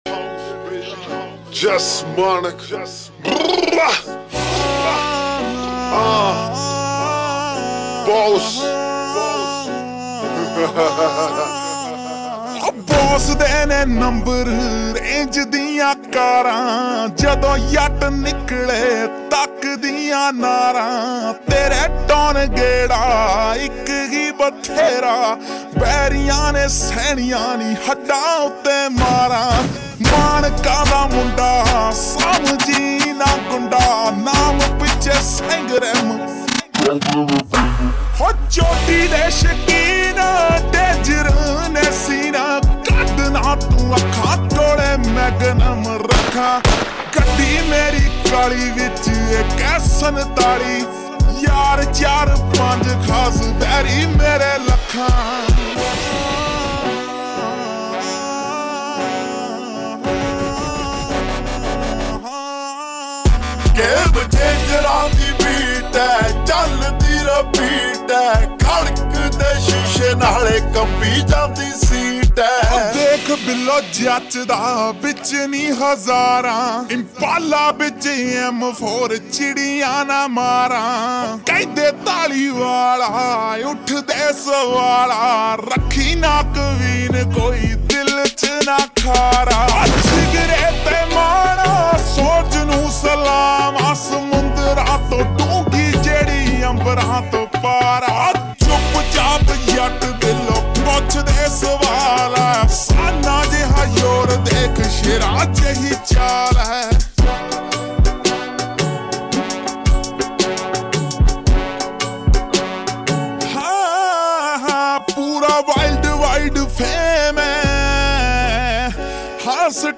(SLOWED REVERB)